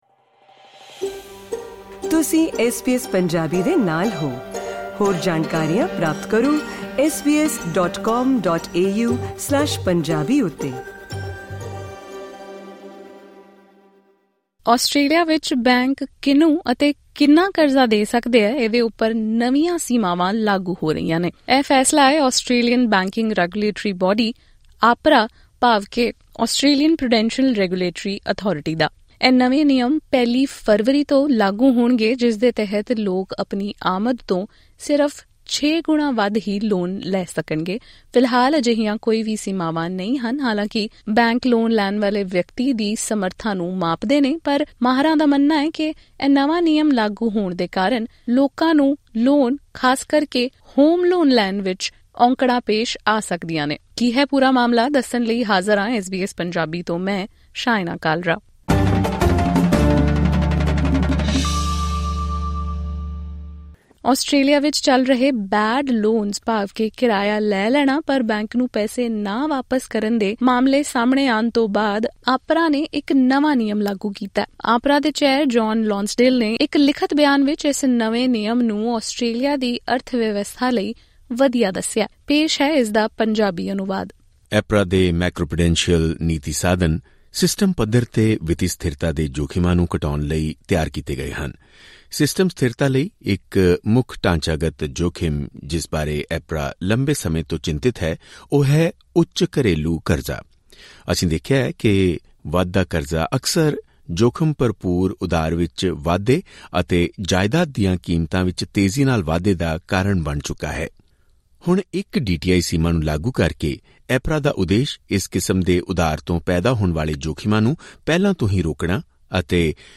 ਆਸਟ੍ਰੇਲੀਆ ਵਿੱਚ ਬੈਂਕਾਂ ਵੱਲੋਂ ਦਿੱਤੇ ਜਾਣ ਵਾਲੇ ਕਰਜ਼ੇ ’ਤੇ ਹੁਣ ਸੀਮਾ ਲਗੇਗੀ, ਜਿਸ ਦਾ ਨਿਰਧਾਰਨ ਆਸਟ੍ਰੇਲੀਆਈ ਪ੍ਰੂਡੈਂਸ਼ੀਅਲ ਰੈਗੂਲੇਸ਼ਨ ਅਥਾਰਟੀ (APRA) ਕਰੇਗੀ। 1 ਫਰਵਰੀ 2026 ਤੋਂ ਲਾਗੂ ਨਿਯਮਾਂ ਅਨੁਸਾਰ, ਬੈਂਕ ਆਮ ਤੌਰ ’ਤੇ ਆਮਦਨ ਤੋਂ ਛੇ ਗੁਣਾ ਤੋਂ ਵੱਧ ਕਰਜ਼ਾ ਨਹੀਂ ਦੇ ਸਕਣਗੇ, ਅਤੇ ਇਹ ਸੀਮਾ ਰਿਫਾਇਨੈਂਸ ’ਤੇ ਵੀ ਲਾਗੂ ਹੋਵੇਗੀ। ਇਹ ਬਦਲਾਅ ਘਰ ਖਰੀਦਣ ਜਾਂ ਲੋਨ ਮੁੜ-ਵਿੱਤ ਕਰਵਾਉਣ ਵਾਲਿਆਂ ਨੂੰ ਕਿਵੇਂ ਪ੍ਰਭਾਵਿਤ ਕਰੇਗਾ, ਅਤੇ ਇਸ ਨਾਲ ਨਿਭਣ ਦੇ ਕੀ ਰਾਹ ਹਨ, ਸੁਣੋ ਐਸਬੀਐਸ ਪੰਜਾਬੀ ਦੀ ਮਾਹਿਰਾਂ ਨਾਲ ਗੱਲਬਾਤ।